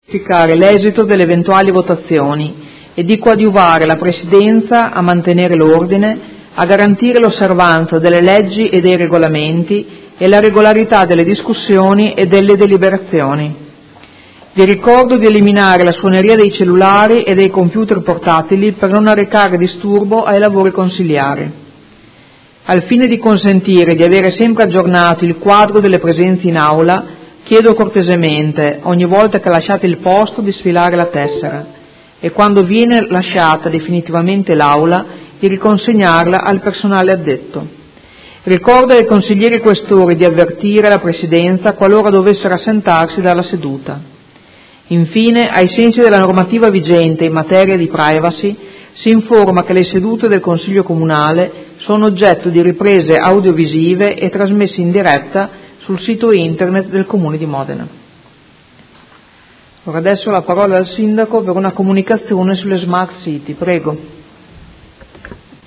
Seduta del 22/09/2016 Apertura del Consiglio Comunale